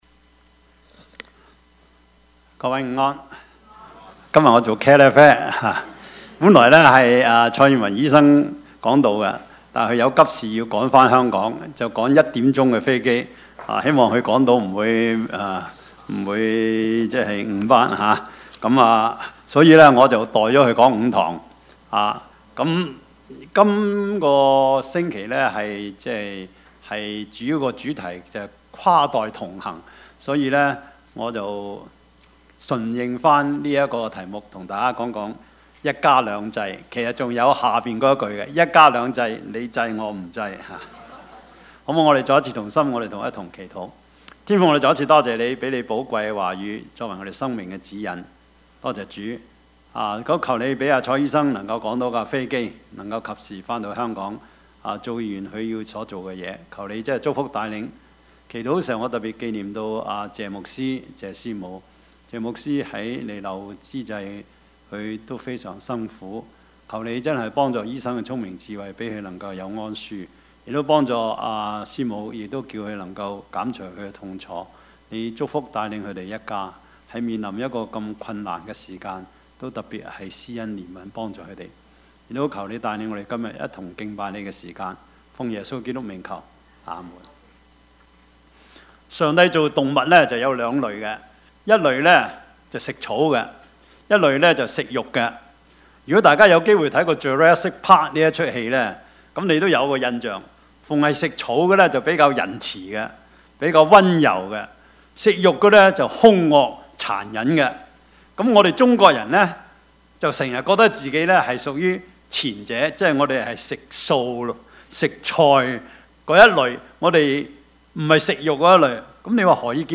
Service Type: 主日崇拜
Topics: 主日證道 « 最大的冤假錯案 真假信徒 »